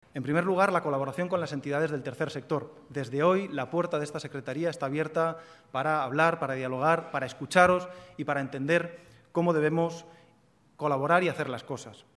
expresó su disposición formato MP3 audio(0,19 MB) al diálogo y abrió sus puertas específicamente a todas las organizaciones del Tercer Sector para “entenderos y aprender de vosotros cómo hacer las cosas”.